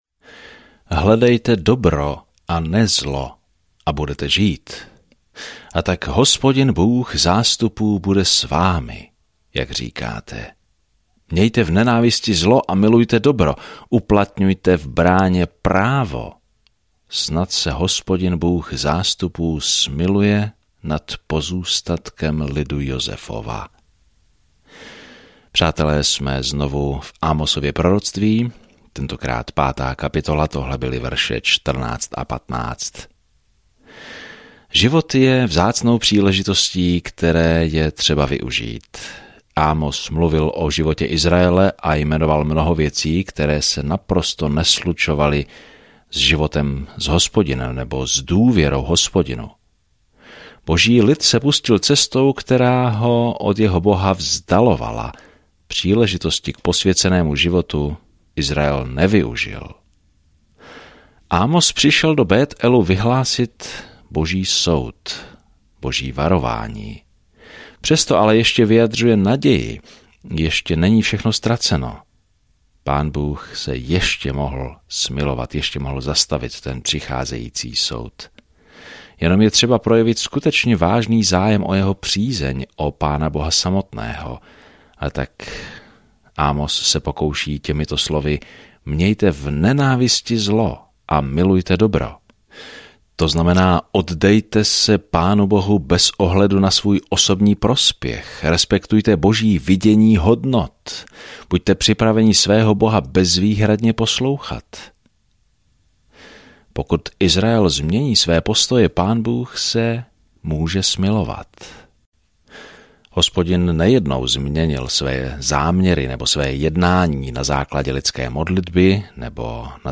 Písmo Amos 5:14-27 Amos 6:1-6 Den 7 Začít tento plán Den 9 O tomto plánu Amos, venkovský kazatel, jde do velkého města a odsuzuje jejich hříšné způsoby a říká, že všichni jsme zodpovědní Bohu podle světla, které nám dal. Denně procházejte Amosem a poslouchejte audiostudii a čtěte vybrané verše z Božího slova.